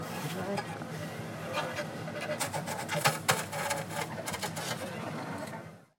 Office Ambience
A modern open-plan office with keyboard typing, phone murmurs, and HVAC hum
office-ambience.mp3